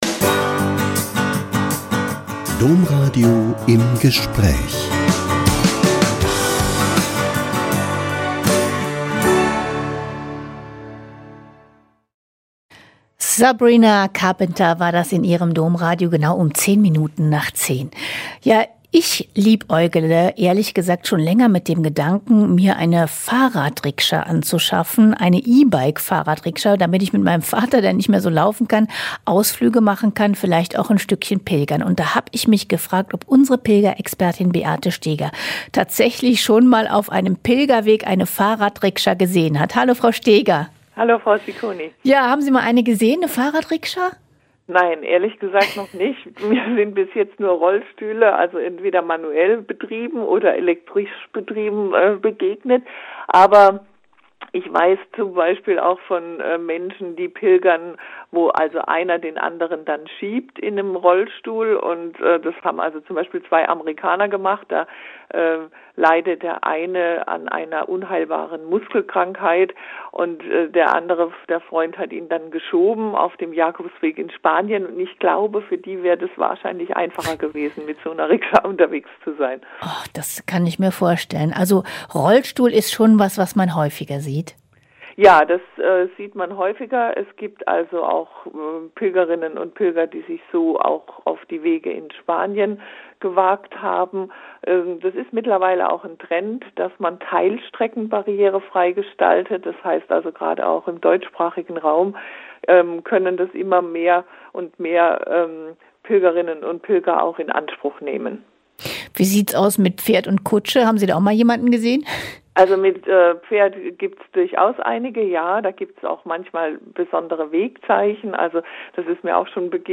Im Interview verrät sie, wie man sich